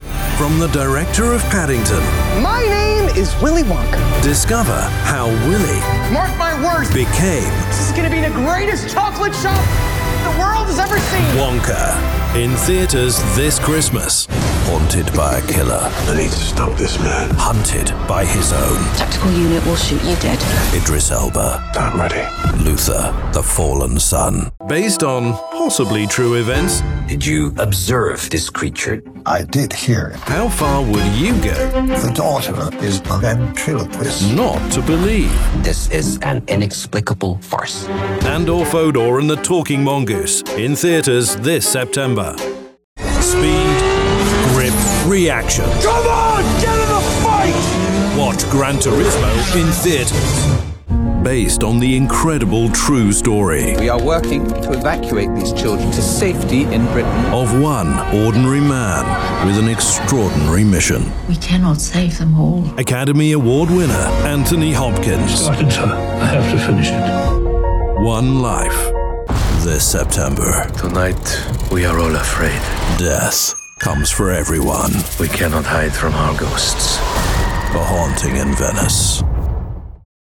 Male
English (British)
Movie Trailers
BaritoneBassDeepLow
ConversationalProfessionalWarmSophisticatedFunnyNeutral